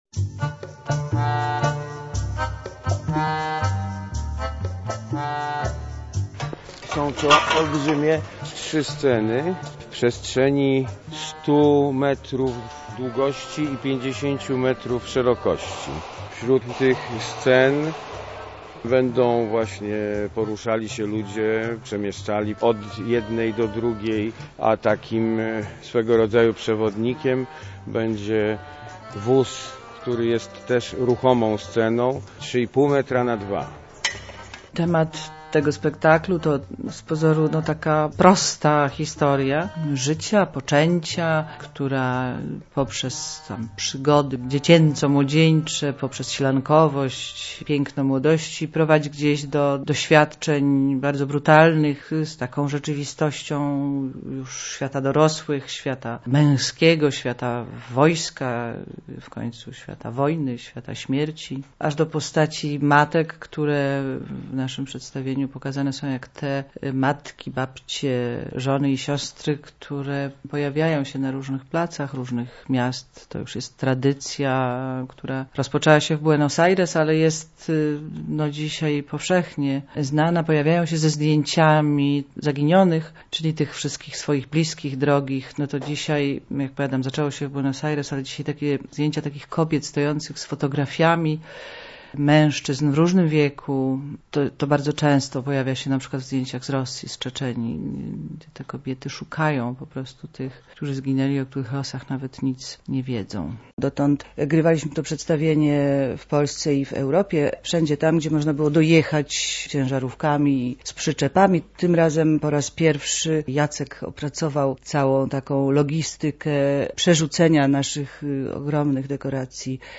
Czas Matek w Chile - reportaż